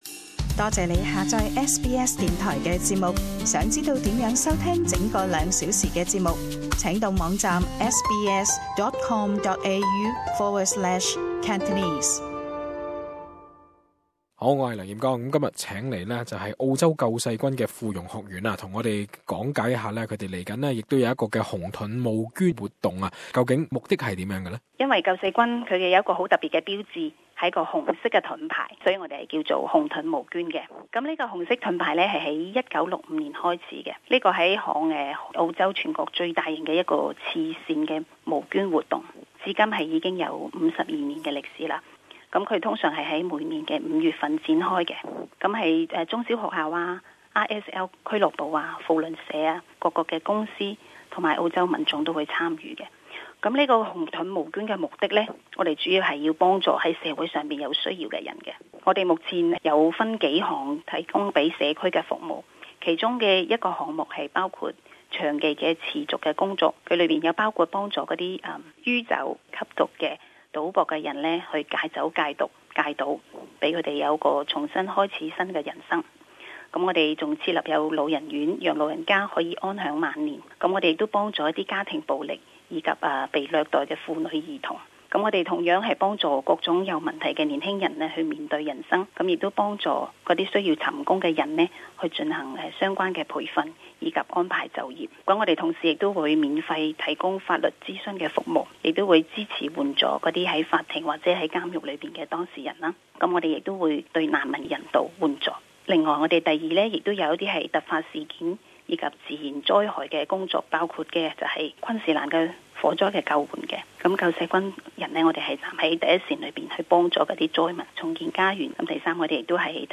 【社區專訪】「救世軍」紅盾募捐活動目的何在?